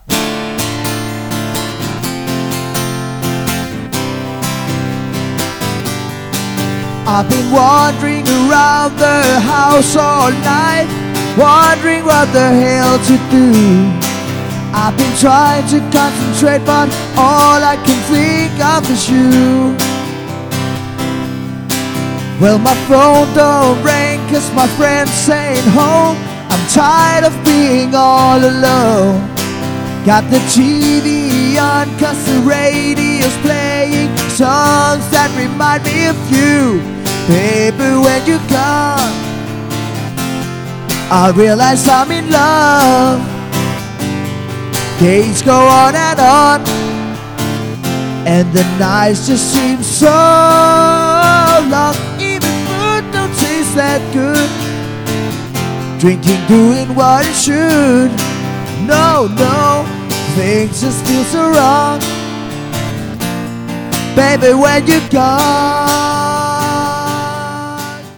• Coverband
• Solomusiker